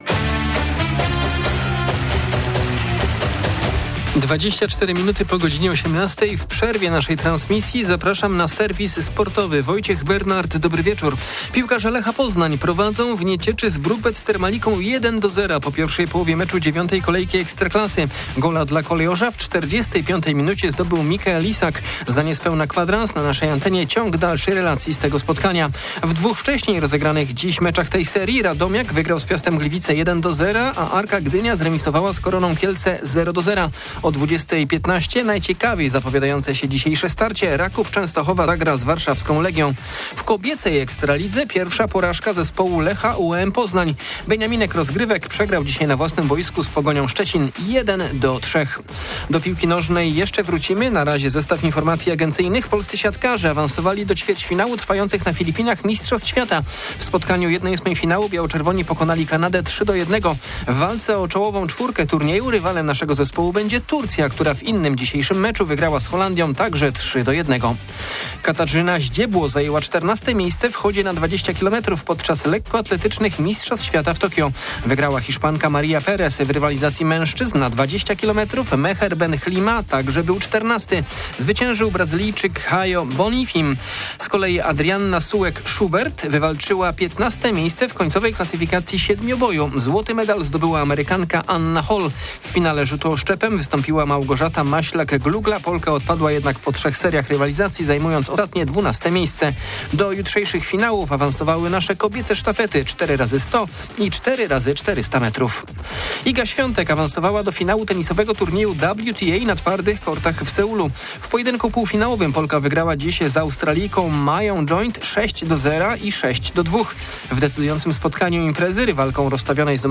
20.09.2025 SERWIS SPORTOWY GODZ. 18:25
Serwis tym razem w przerwie naszej transmisji meczu Kolejorza, a w nim również o poznańskiej Warcie i futsalowej drużynie Wiary Lecha. Zajrzymy też na siatkarskie parkiety i tenisowe korty.